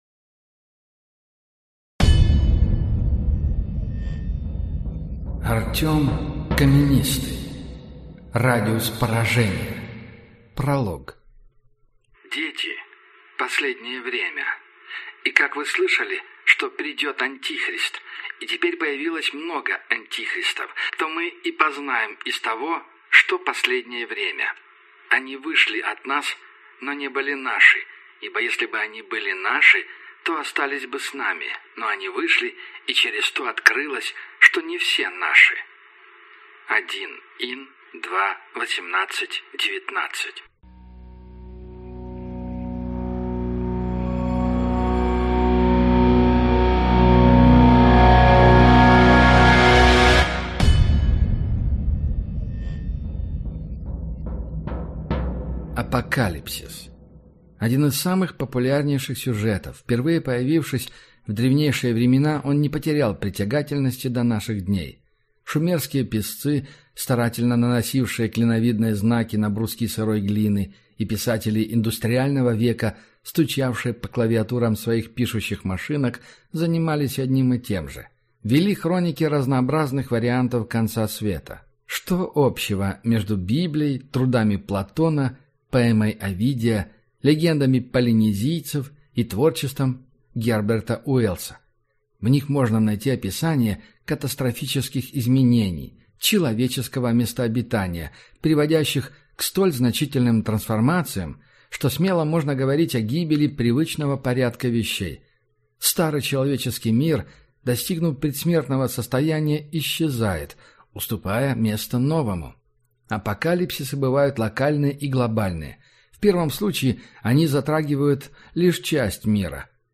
Аудиокнига Радиус поражения | Библиотека аудиокниг